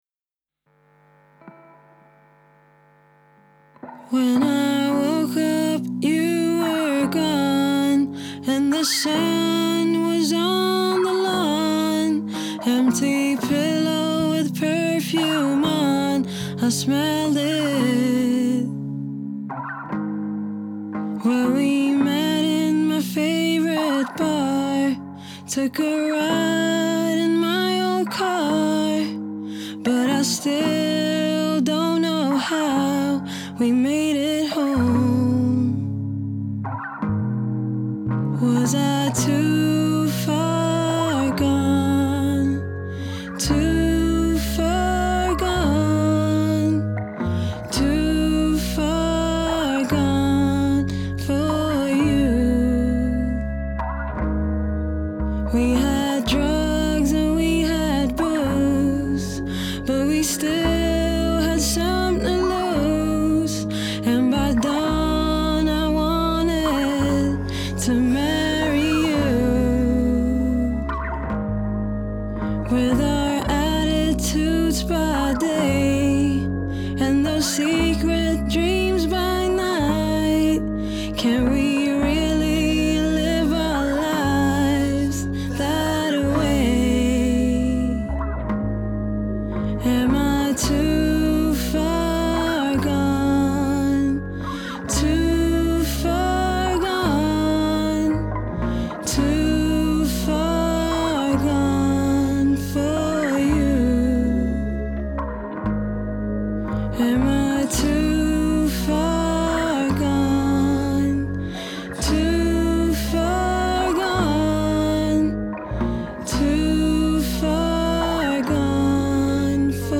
Genre: Indie Pop, Rock, Female Vocal